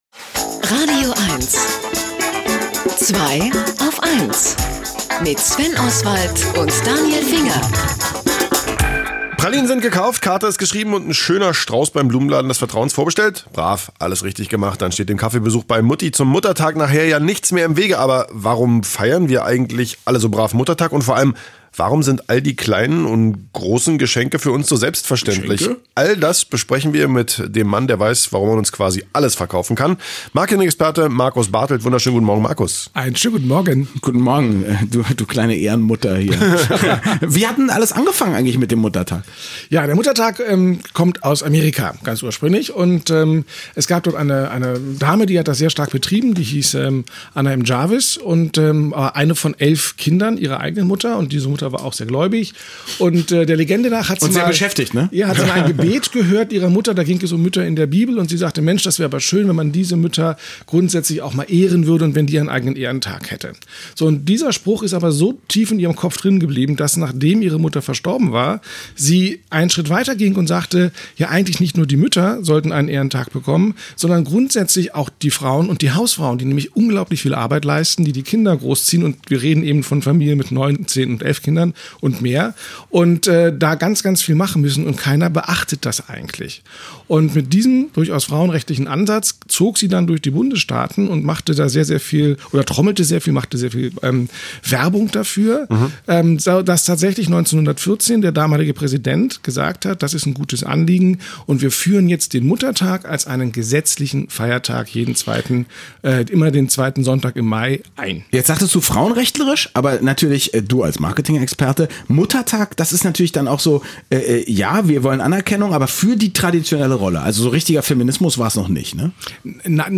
Die Radio-Interviews seit 2010